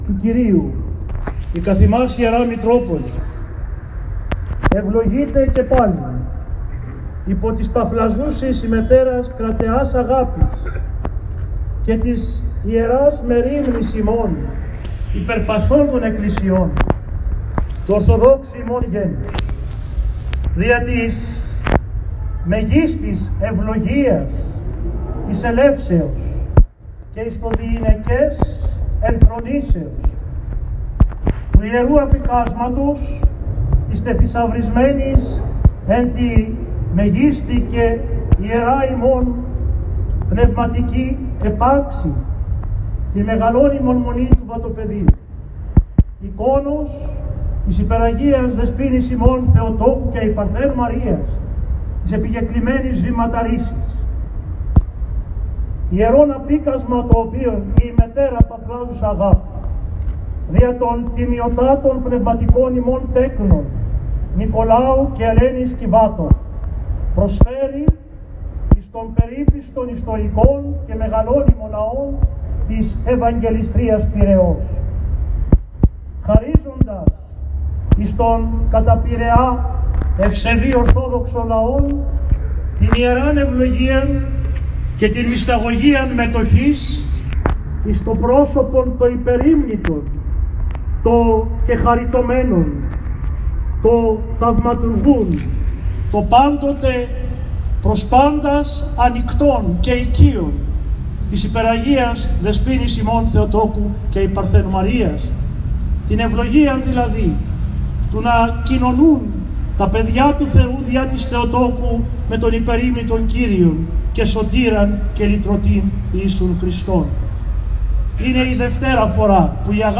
Ακούστε στο παρακάτω ηχητικό ένα απόσπασμα από την ομιλία του Σεβ. Μητροπολίτη Πειραιώς κ. Σεραφείμ κατά την υποδοχή της Ιεράς Εικόνας της Παναγίας της Βηματάρισσας στο προαύλιο του Γηροκομείου Πειραιά:
Μητρ-Σεραφείμ.m4a